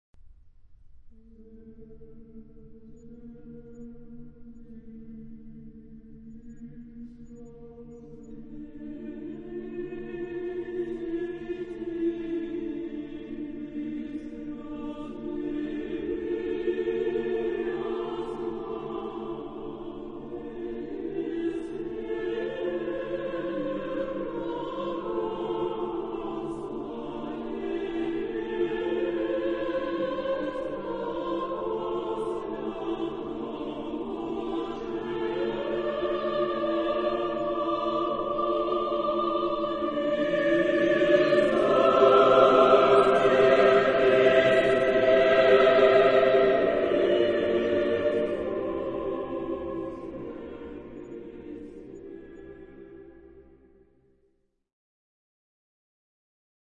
SSAATTBB (7 voix mixtes) ; Partition de choeur et réduction piano pour répétition.
Hymne liturgique (orthodoxe).
Genre-Style-Forme : Sacré ; Hymne liturgique (orthodoxe) ; Orthodoxe Caractère de la pièce : solennel ; majestueux ; pieux
Tonalité : ré mineur